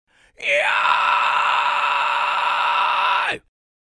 Fry Scream complexe Basique tonal
19Fry-Scream-complexe-_-Basique_tonal.mp3